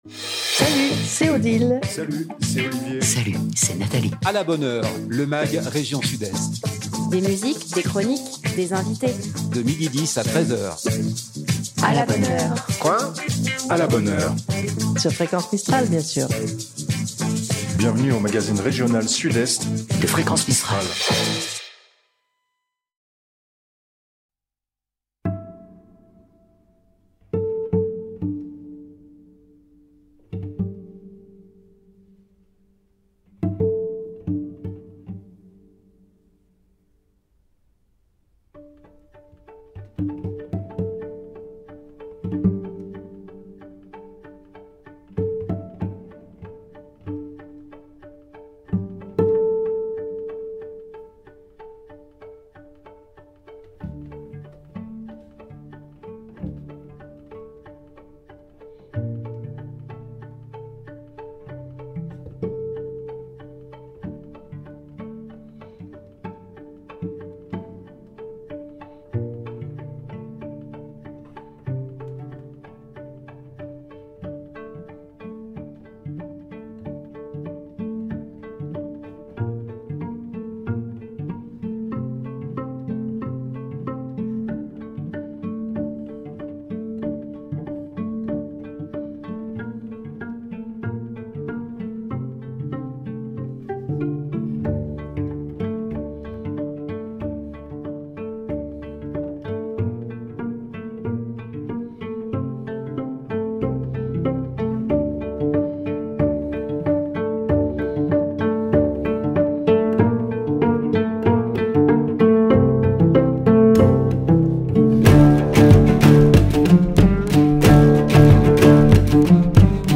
Duo Brady, duo de violoncelles, Dans le mag "A la bonne heure !"
des invité.e.s en direct